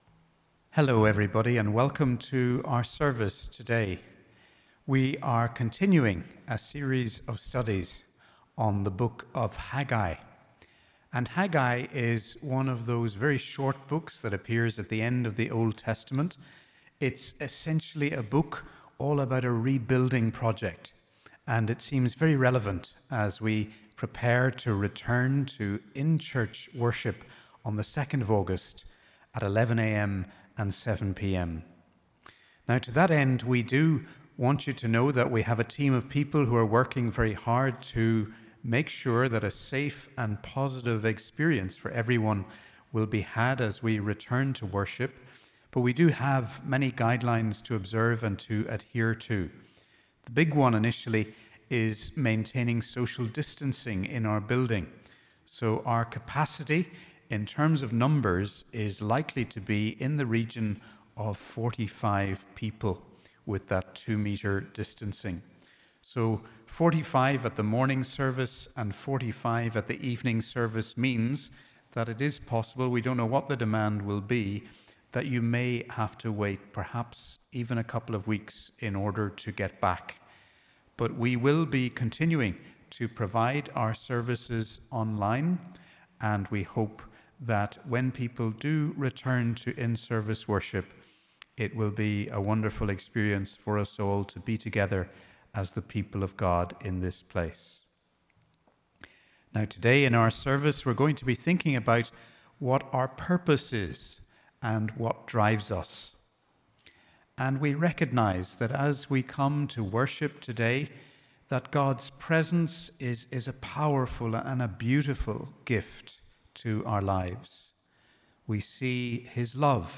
Welcome to our service for Sunday 12th July – Trinity 5, as we continue our study of the book of Haggai.